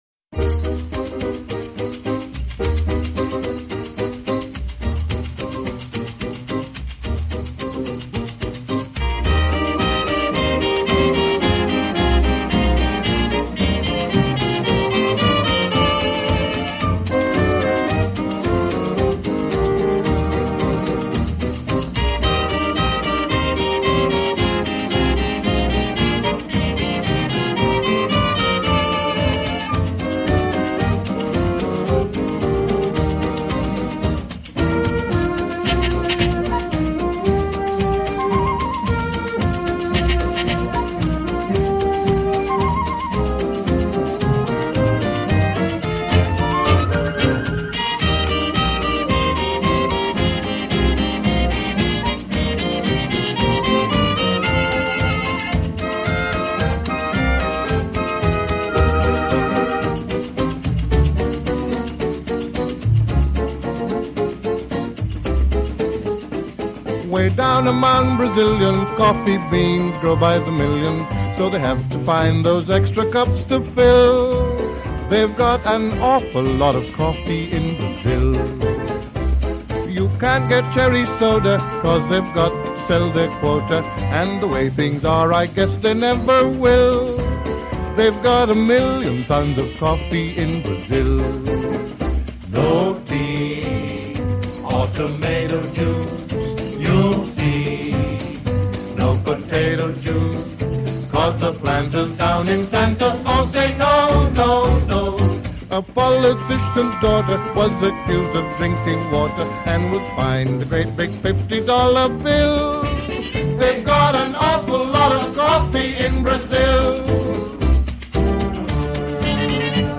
Jazz Quality: MP3 VBR V2 kbps 44khz